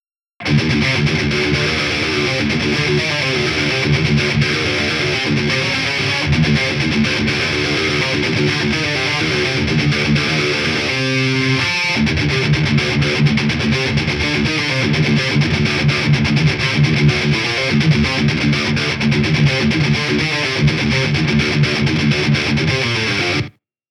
Накручиваем тяж метальный звук на гитарах!